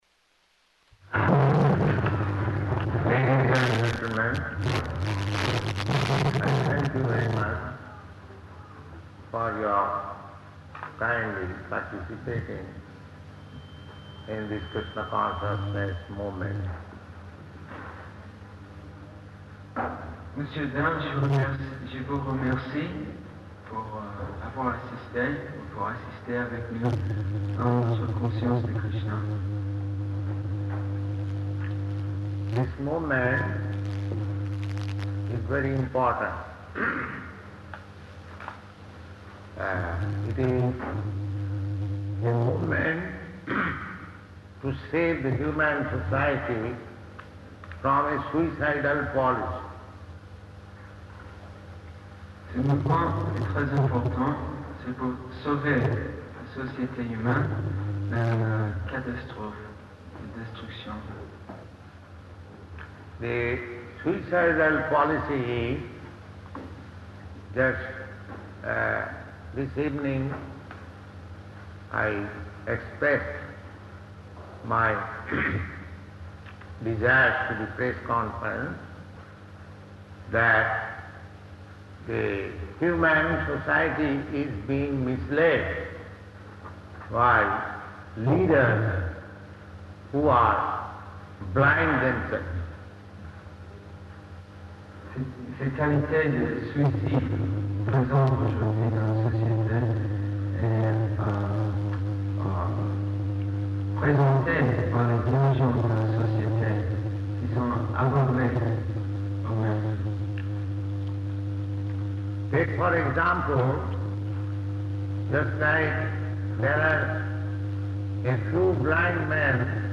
Type: Lectures and Addresses
Location: Paris
[Bad Distortion] [translated throughout by devotee]